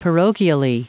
Prononciation du mot parochially en anglais (fichier audio)
Prononciation du mot : parochially